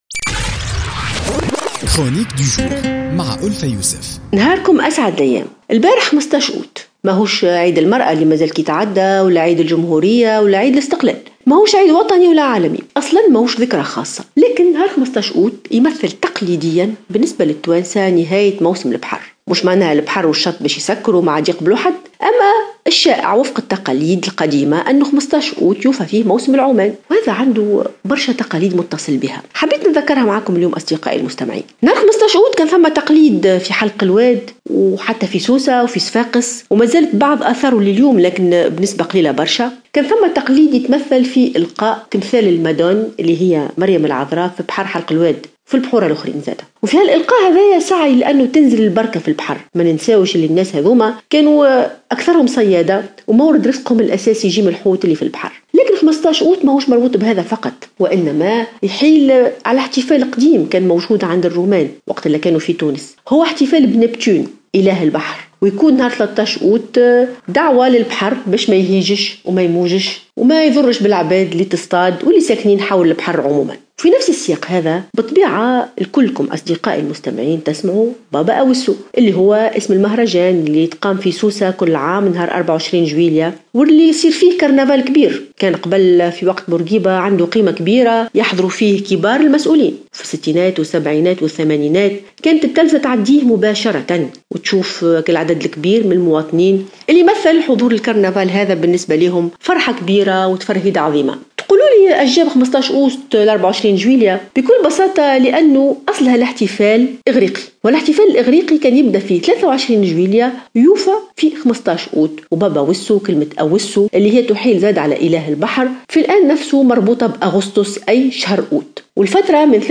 تطرقت الأستاذة ألفة يوسف في افتتاحية اليوم الثلاثاء إلى الدلالات والأبعاد الرمزية ليوم 15 أوت من كل عام مشيرة إلى أن هذا التاريخ يمثل بالنسبة للتونسيين نهاية موسم البحر وفقا للتقاليد القديمة للشارع التونسي .